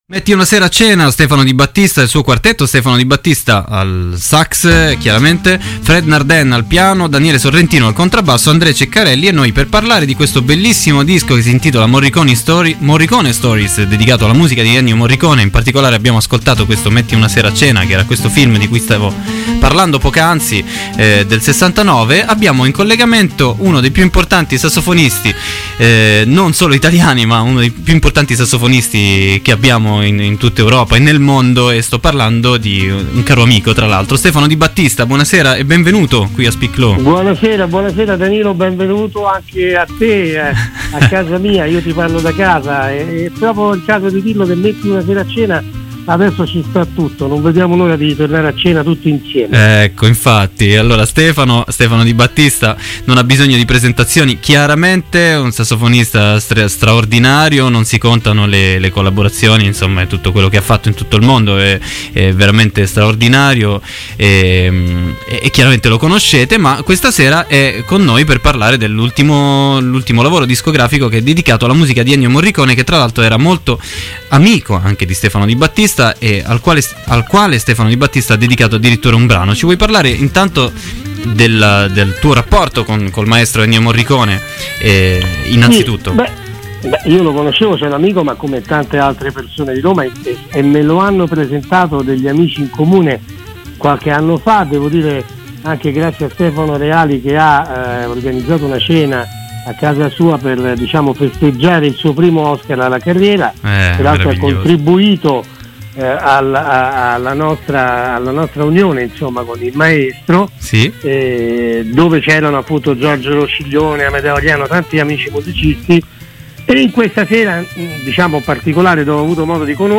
Intervista a Stefano Di Battista. Speak Low 16.04.21 | Radio Città Aperta